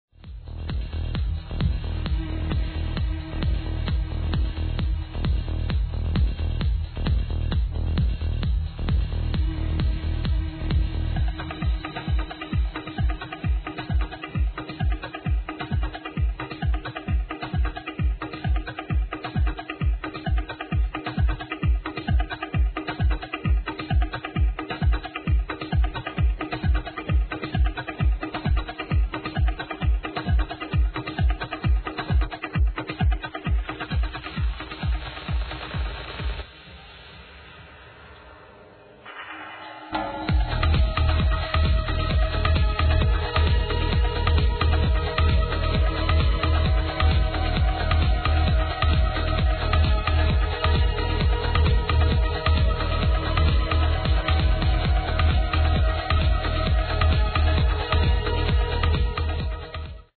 Last proggy for the night